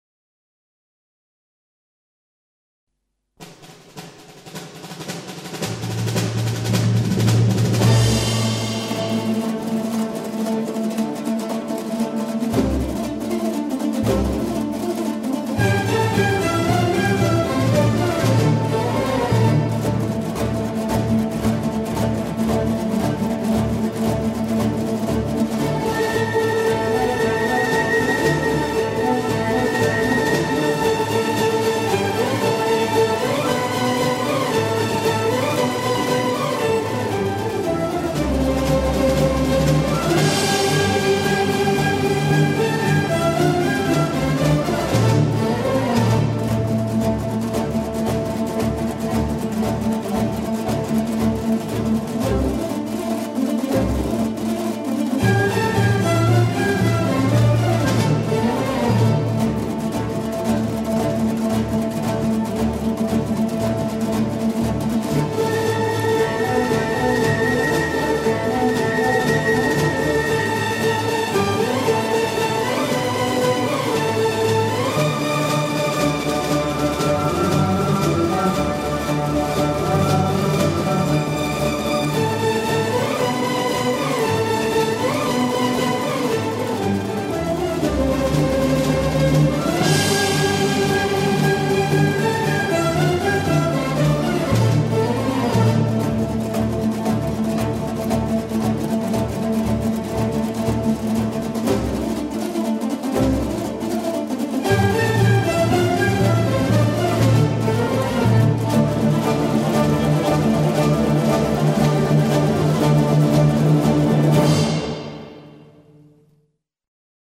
• Качество: 128, Stereo
восточные мотивы
без слов
инструментальные
Народные
оркестр
домбра